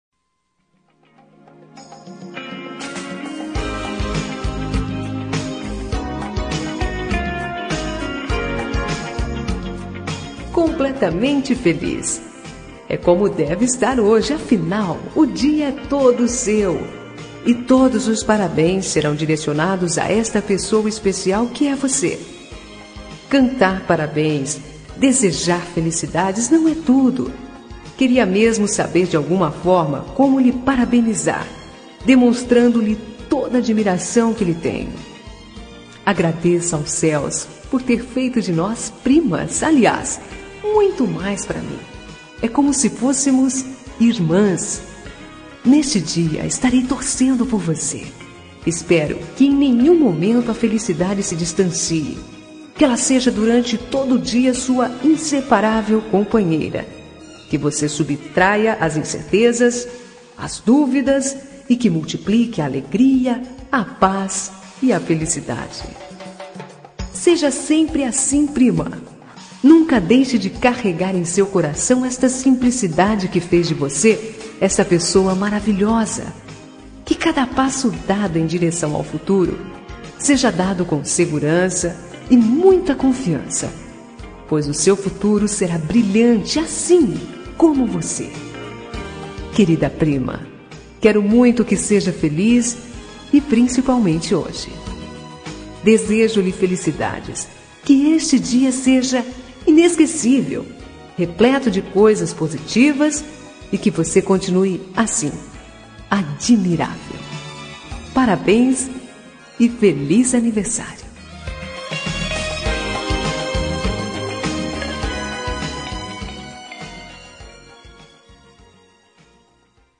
Aniversário de Prima – Voz Feminina – Cód: 042807